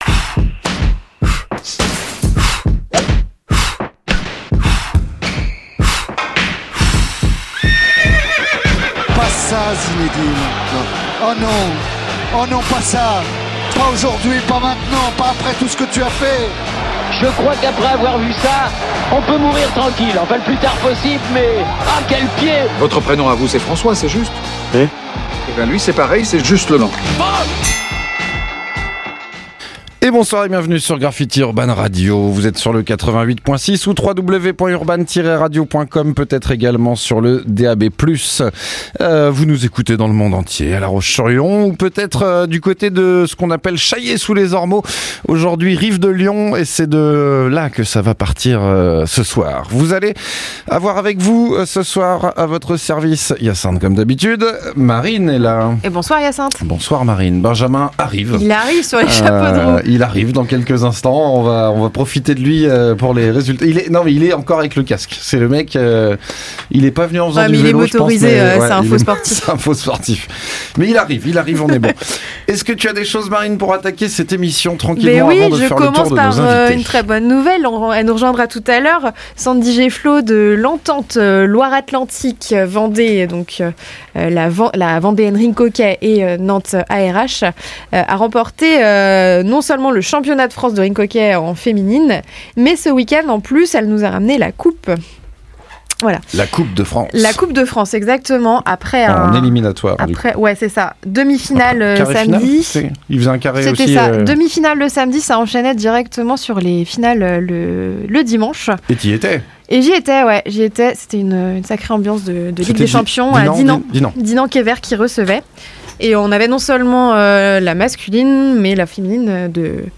L'émission du sport en Vendée sur Graffiti le mardi de 19h à 20h.